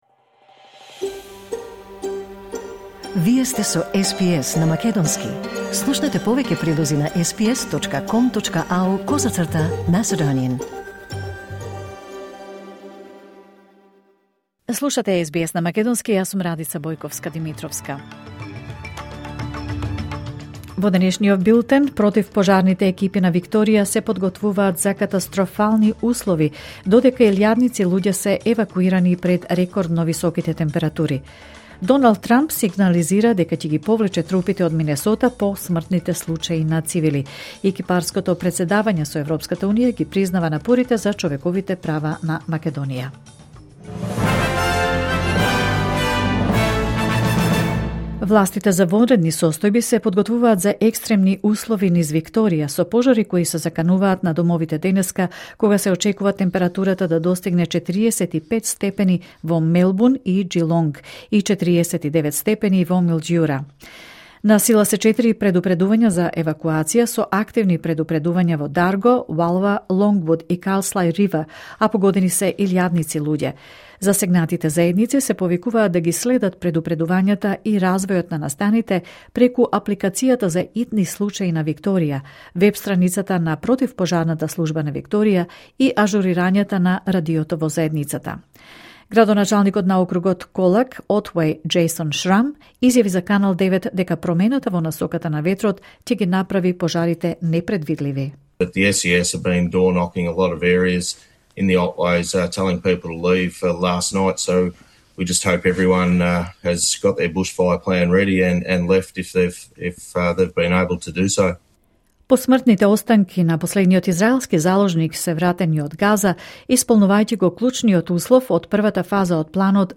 Вести на СБС на македонски 27 јануари 2026